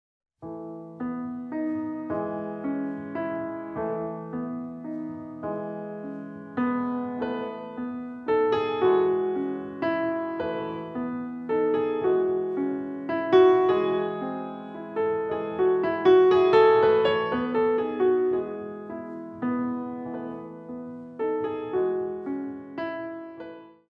4. Slow Battements Tendus